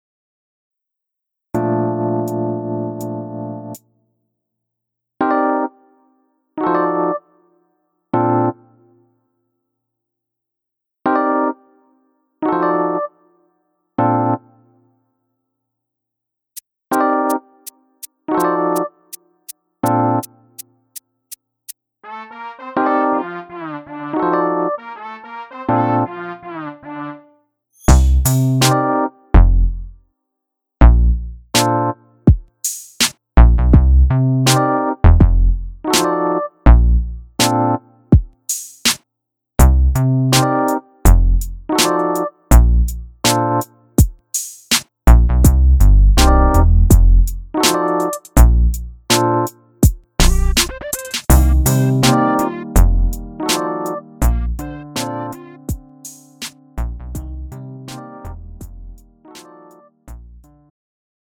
음정 원키 3:16
장르 가요 구분 Pro MR